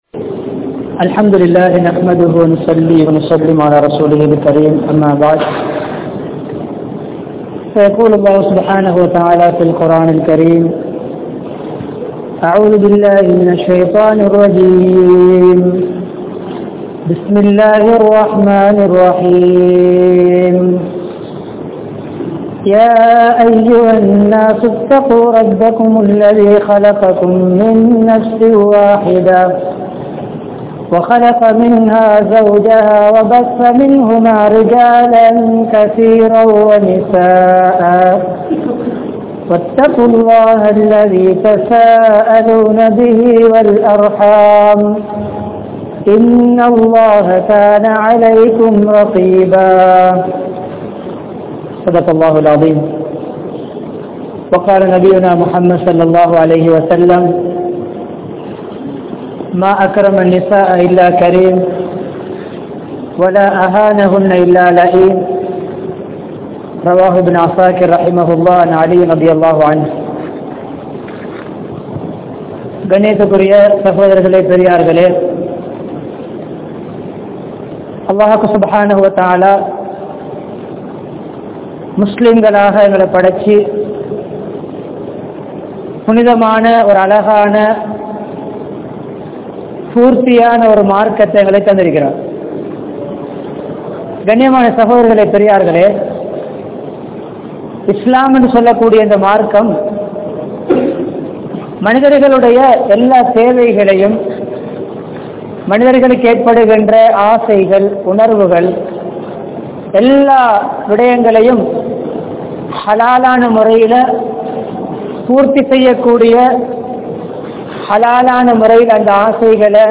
Mahilchiyaana Kudumba Vaalkai (மகிழ்ச்சியான குடும்ப வாழ்க்கை) | Audio Bayans | All Ceylon Muslim Youth Community | Addalaichenai
Colombo 04, Majma Ul Khairah Jumua Masjith (Nimal Road)